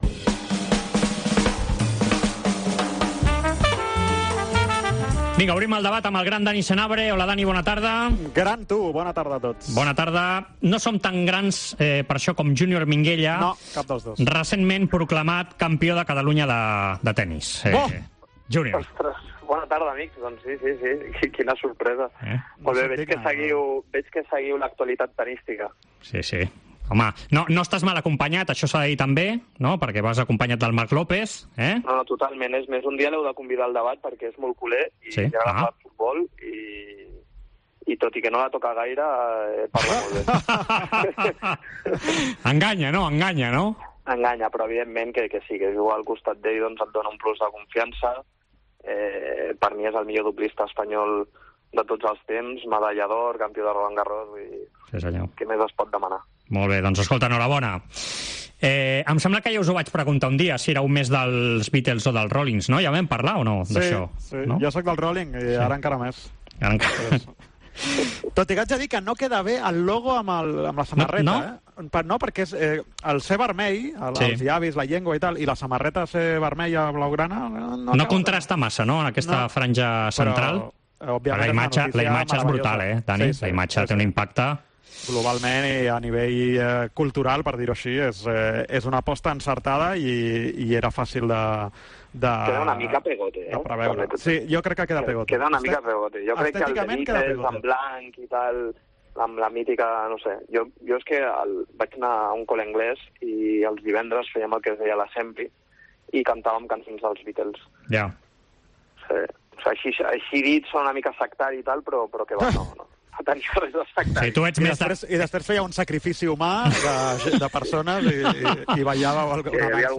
AUDIO: Els dos col·laboradors de la Cadena COPE repassen l'actualitat esportiva d'aquesta setmana.